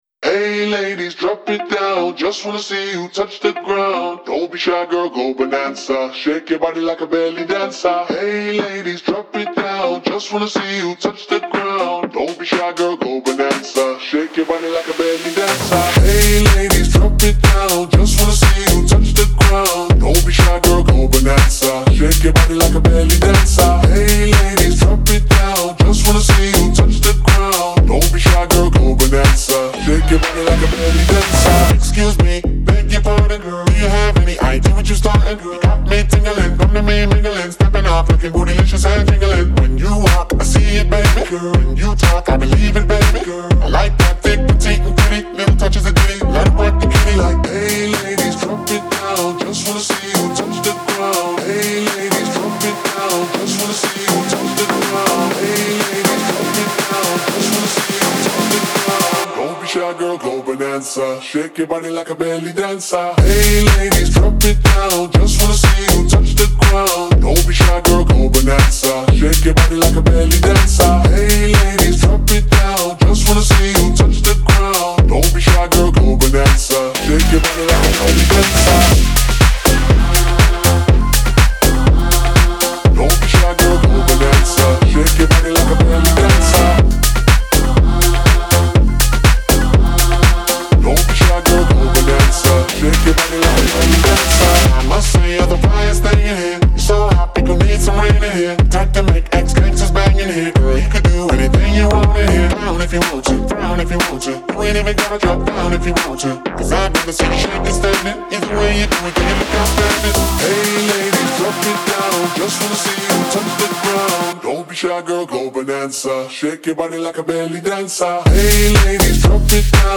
Genre : Electro, Alternative